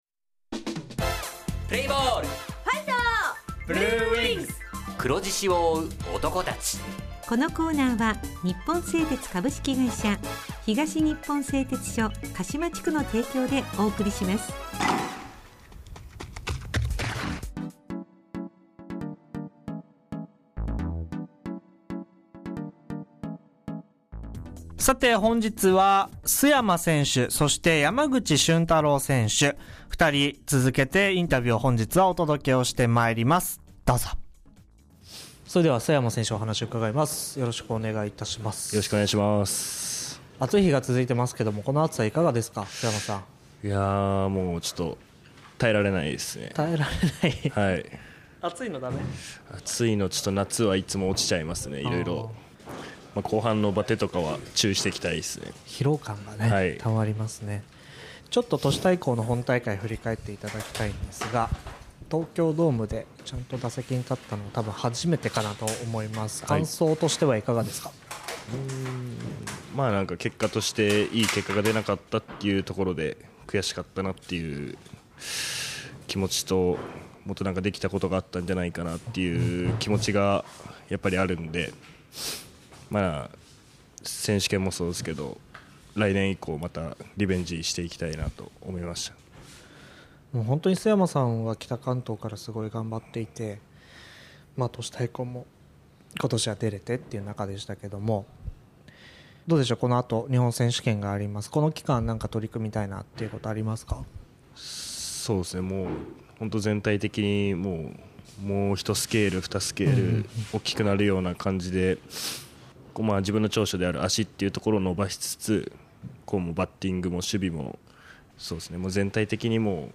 地元ＦＭ放送局「エフエムかしま」にて当所硬式野球部の番組放送しています。